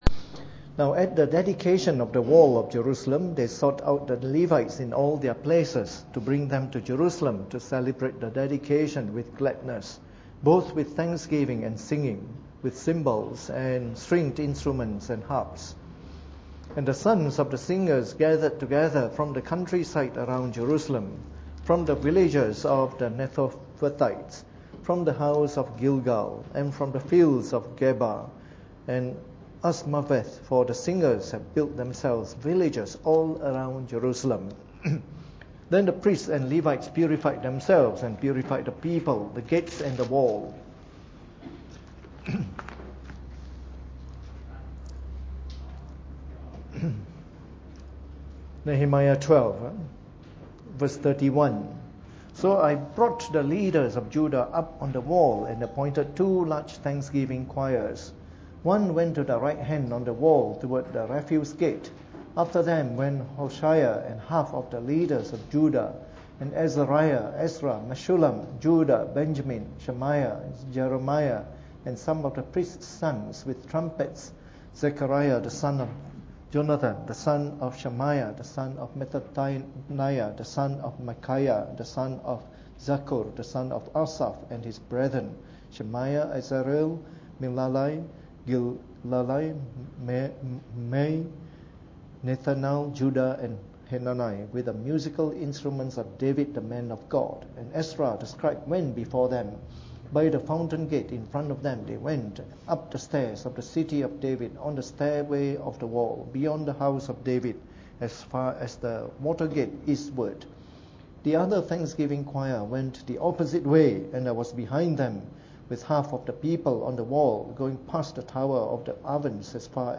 Preached on the 23rd of July 2014 during the Bible Study, from our series of talks on the Book of Nehemiah.